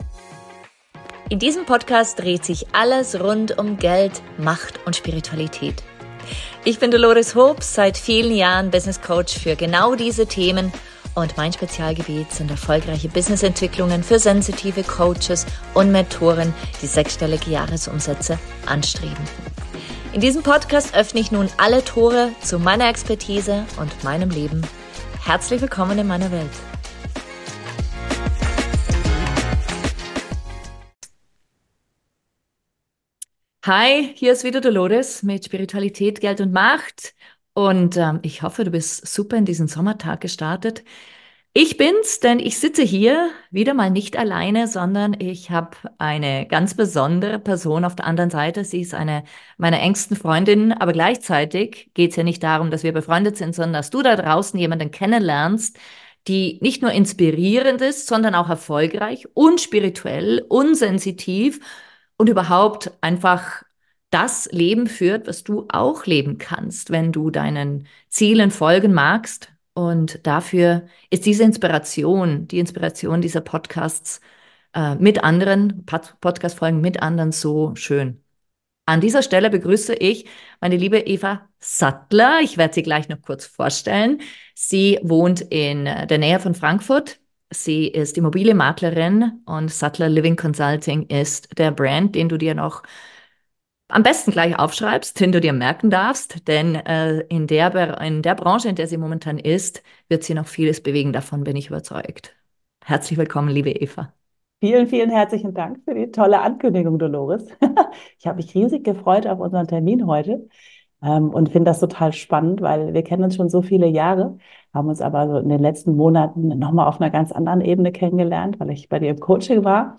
Ein wunderschönes Interview über Frausein, innere Balance, Sensitivität und gesunden Erfolg erwartet dich.